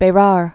(bā-rär, bə-)